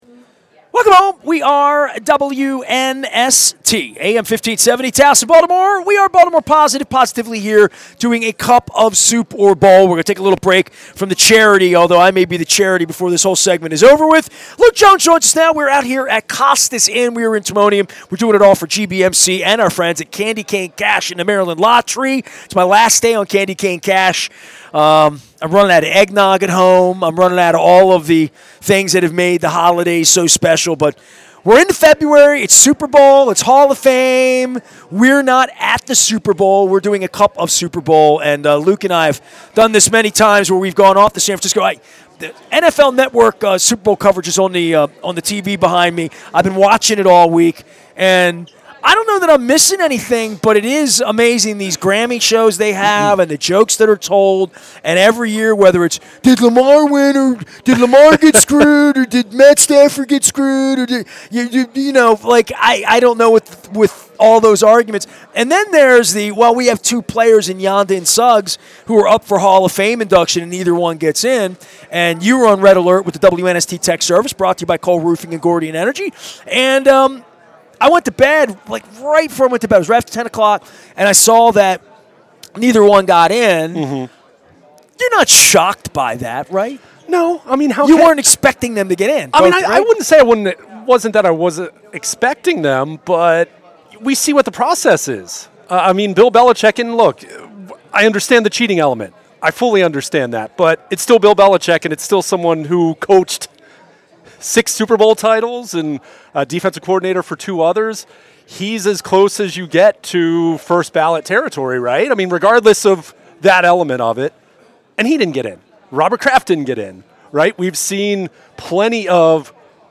at Costas Inn in Timonium